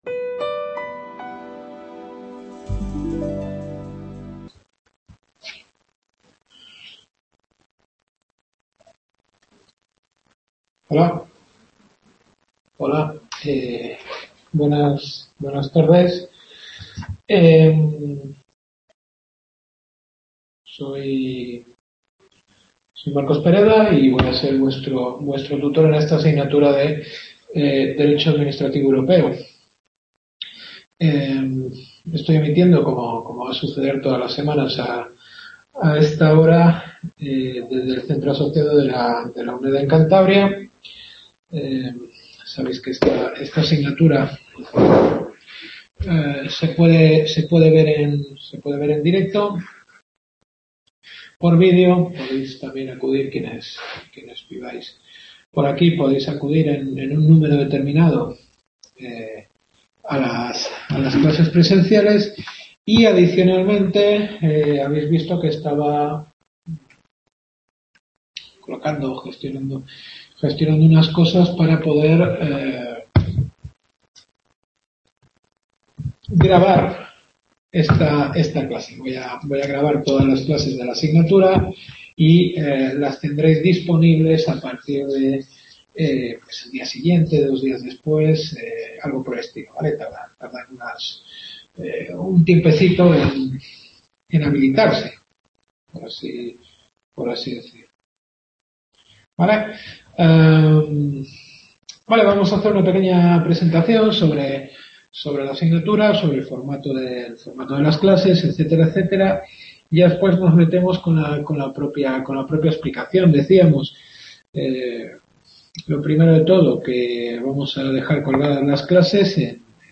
Derecho administrativo Europeo. Primera clase.